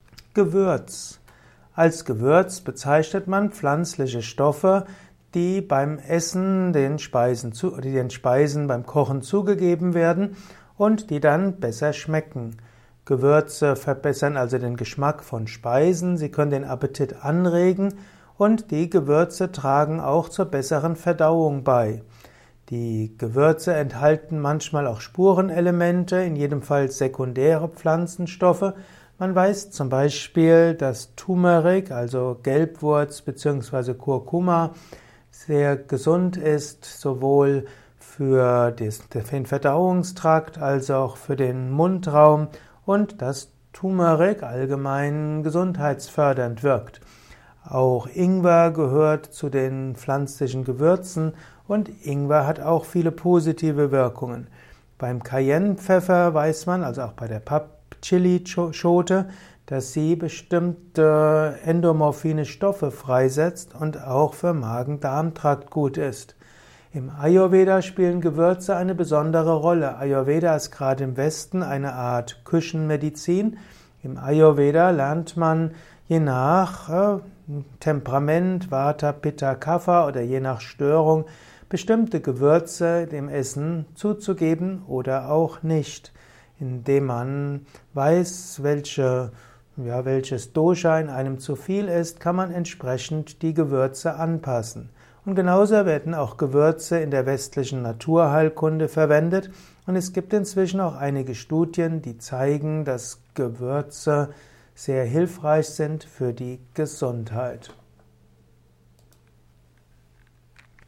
Ein Kurzvortrag über den Begriff Gewürz